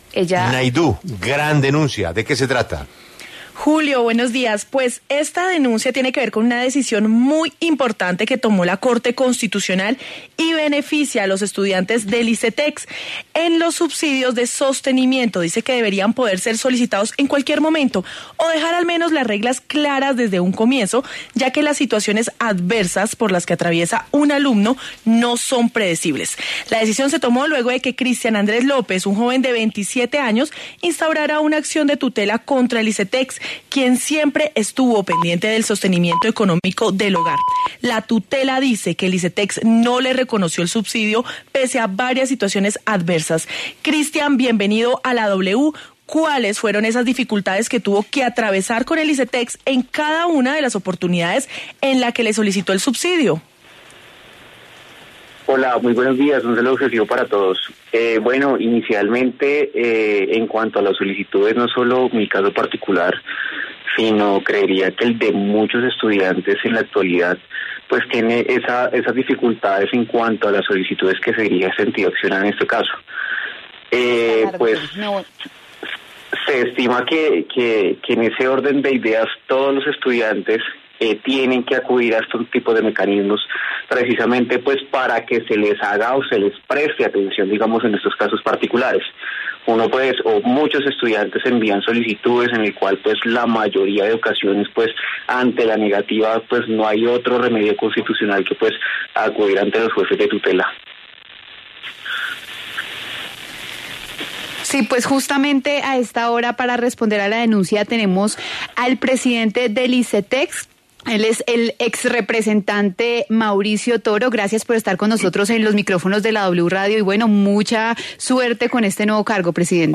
El nuevo presidente del Icetex habló en La W sobres las necesidades que hay en la entidad y los cambios que implementará en su administración.